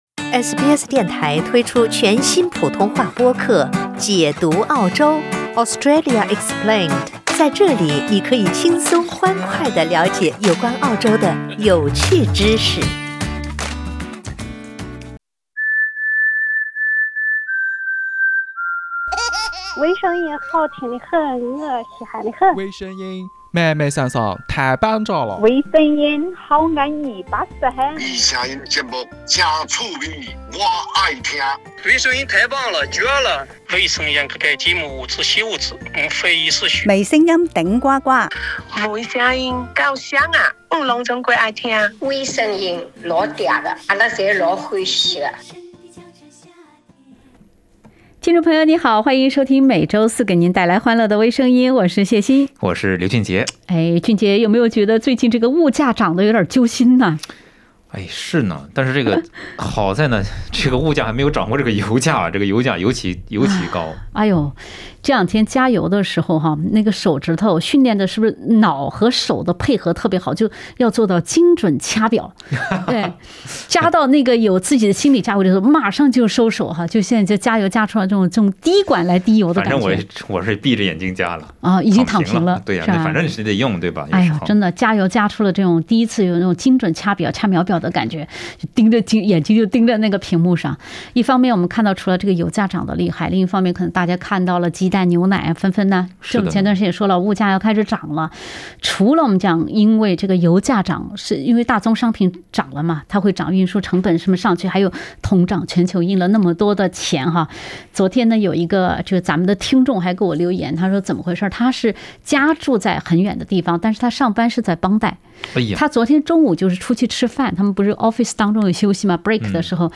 （点击封面图片，收听轻松对话）